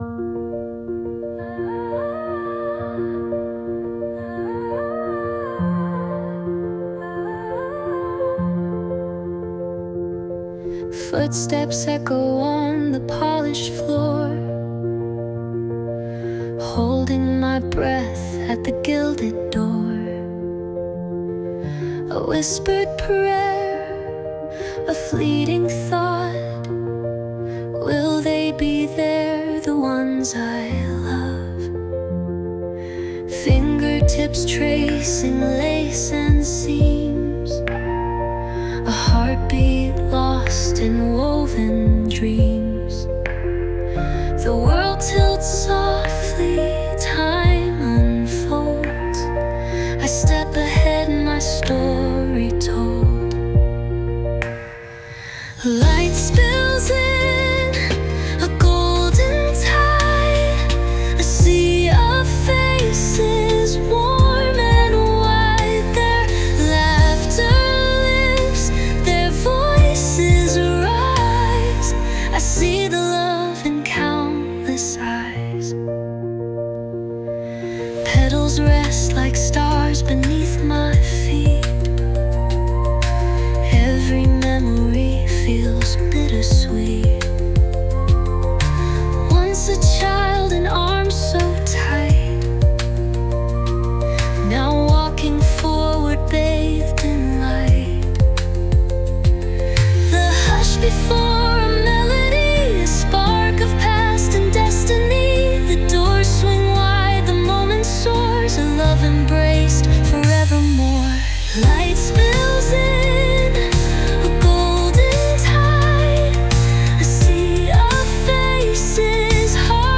洋楽女性ボーカル著作権フリーBGM ボーカル
女性ボーカル洋楽洋楽 女性ボーカルエンドロール入場・再入場バラード華やか優しい
女性ボーカル（洋楽・英語）曲です。